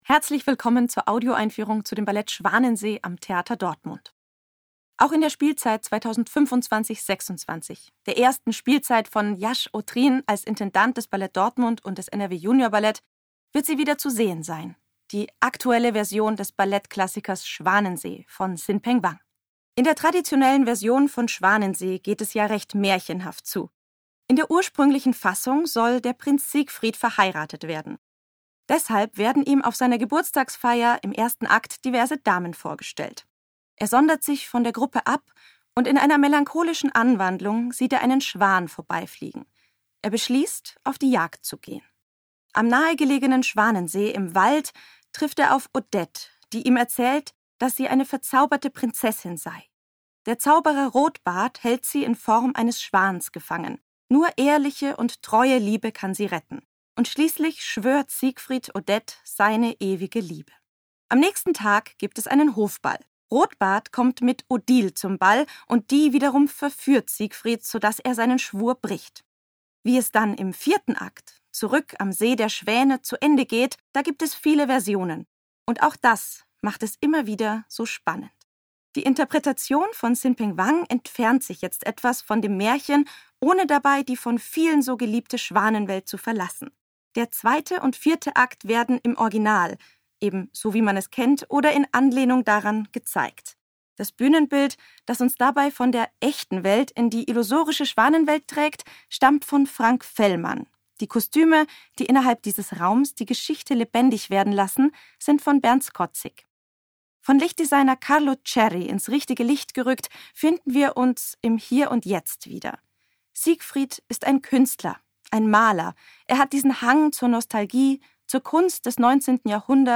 tdo_Audioeinfueehrung_WA_Schwanensee.mp3